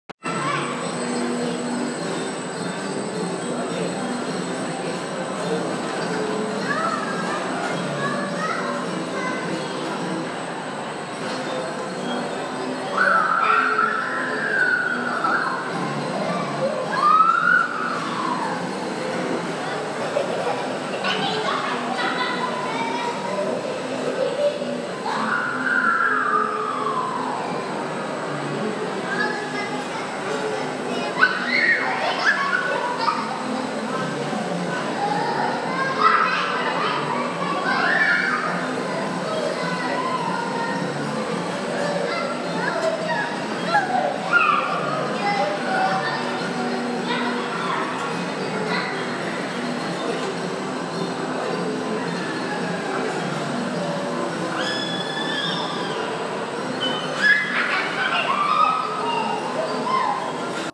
Kids On A Merry-Go-Round
kids-on-a-merry-go-round.m4a